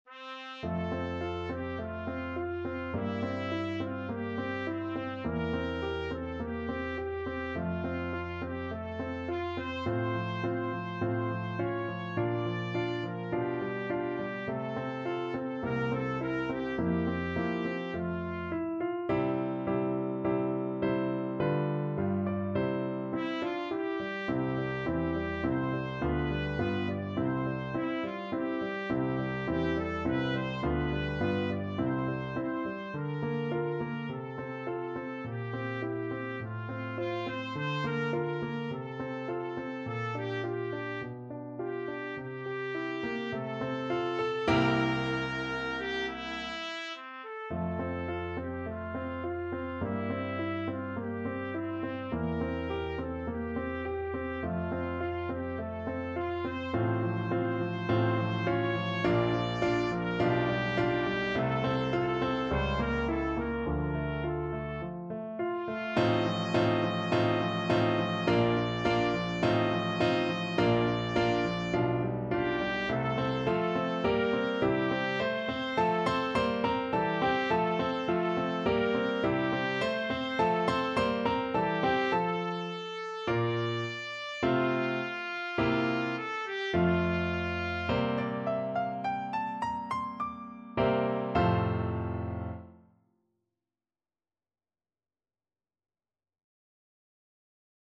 Free Sheet music for Trumpet
Trumpet
C5-Eb6
= 52 Andante
2/4 (View more 2/4 Music)
F major (Sounding Pitch) G major (Trumpet in Bb) (View more F major Music for Trumpet )
Classical (View more Classical Trumpet Music)